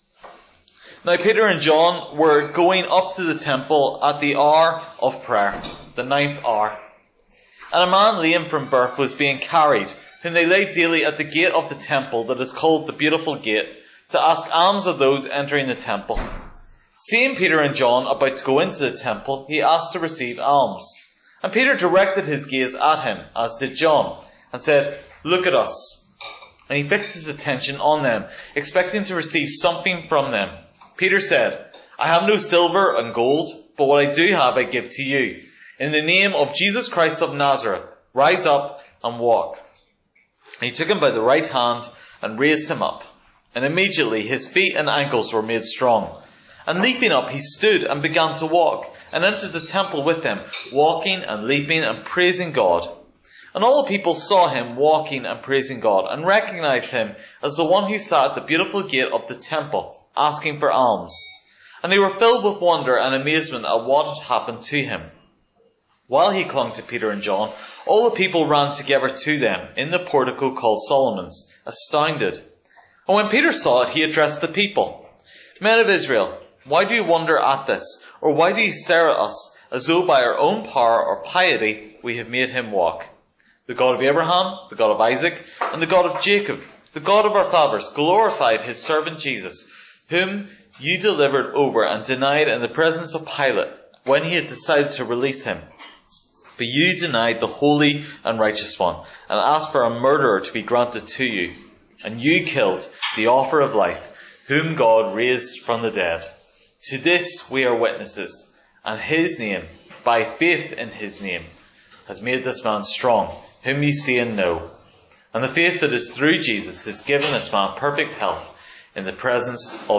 Guest Interviews: Can self help really save?